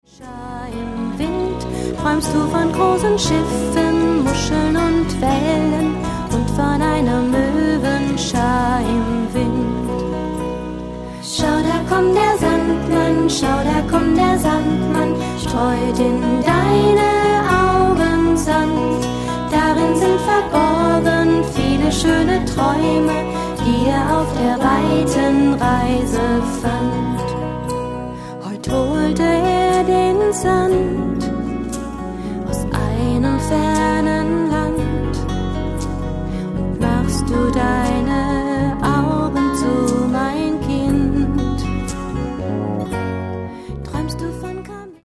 Die schönsten Gute-Nacht-Lieder
• Sachgebiet: Kinderlieder